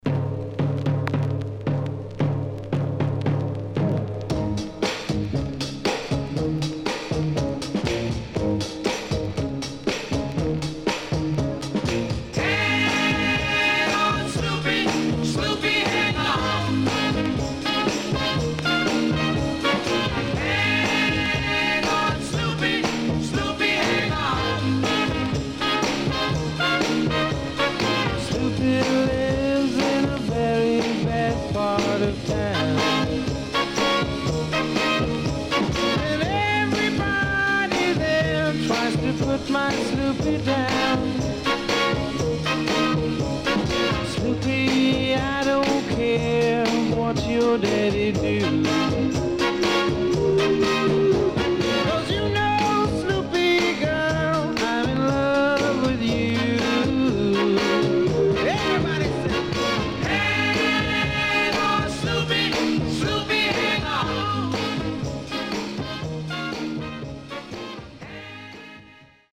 HOME > SKA / ROCKSTEADY  >  SKA
SIDE B:全体的にチリノイズがあり、所々プチノイズ入ります。少し音割れあり。